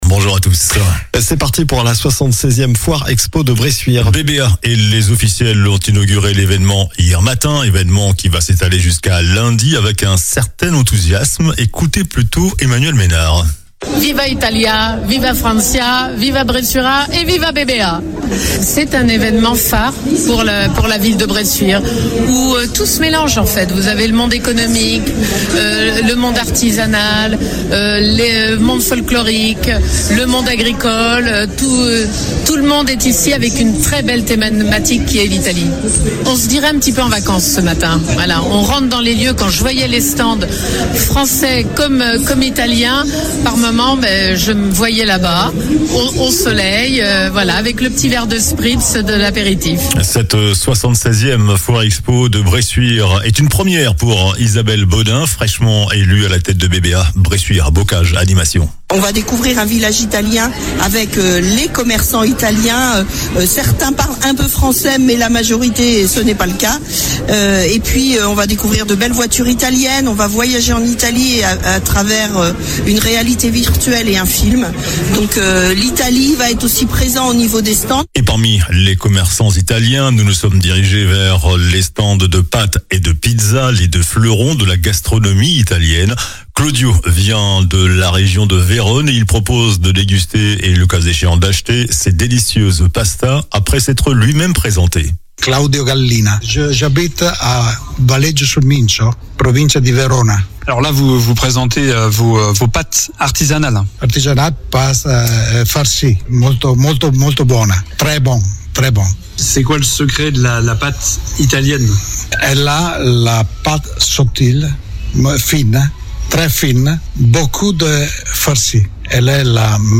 JOURNAL DU SAMEDI 21 MARS